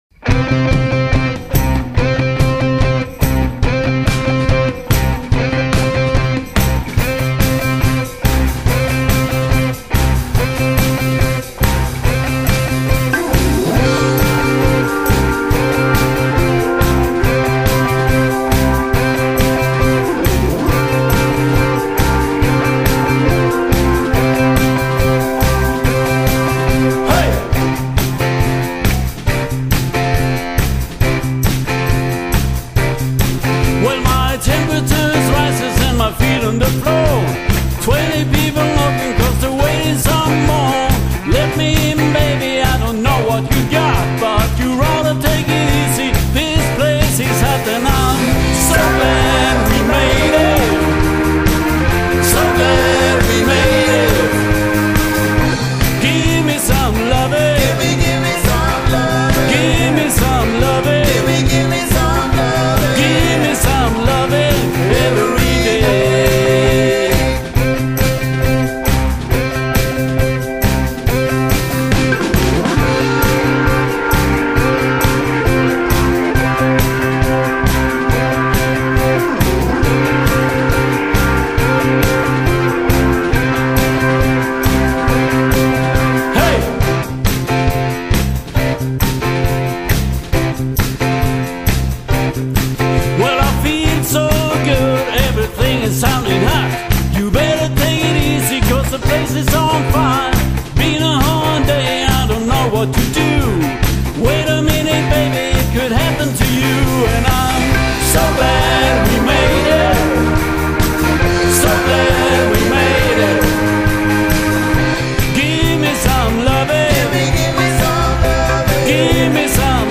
Musik – StudioInspelat – Live
Gitarr & Sång
Bas & Sång
HammondL100/Be-prep./Piano
Trummor & Sång